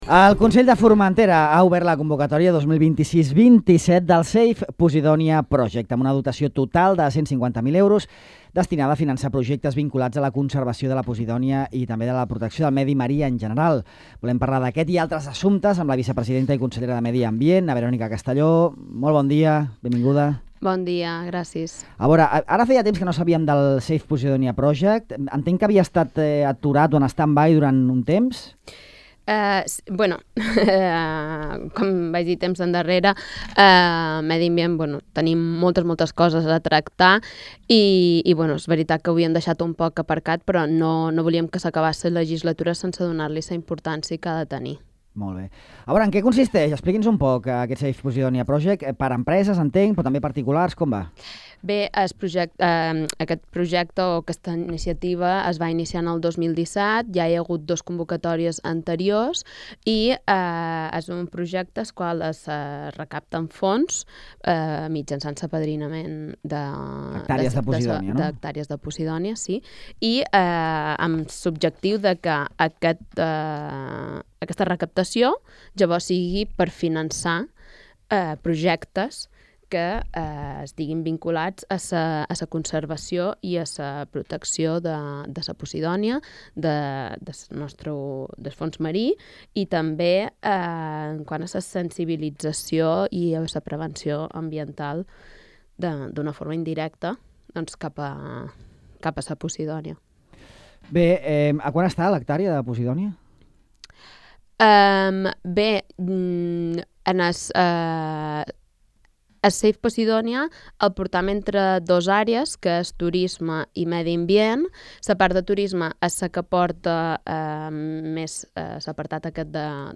En aquesta entrevista a Ràdio Illa, Castelló apunta que el full de ruta passa per la creació d’un servei públic específic per al tractament d’aquests residus, una passa administrativa imprescindible abans de poder licitar la infraestructura necessària.